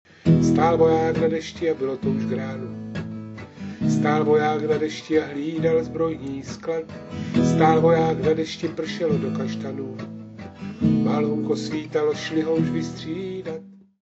country.mp3